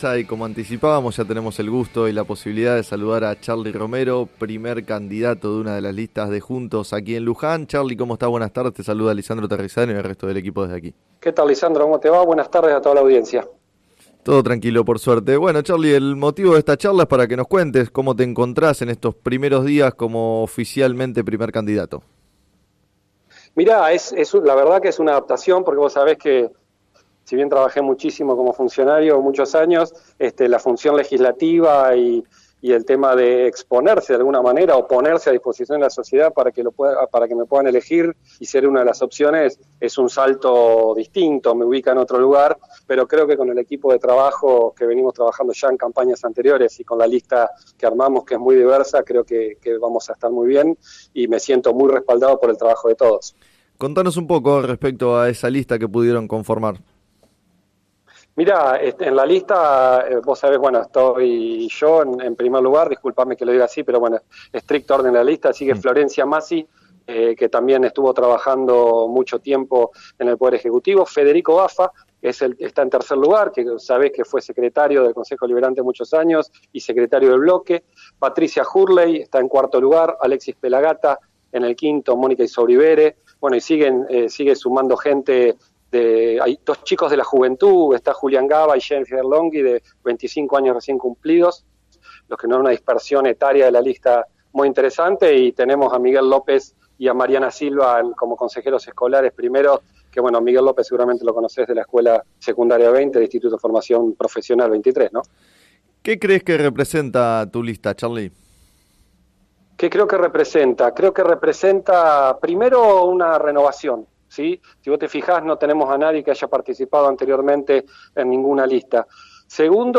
En declaraciones al programa “Sobre las cartas la mesa” de FM Líder 97.7, Romero también consideró que “de las PASO va a surgir una lista de unidad elegida por la gente y no por los dirigentes” y comparó la situación de Juntos con la de un cine que presenta varios estrenos.